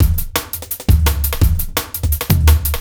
cw_170_EzDrumma.wav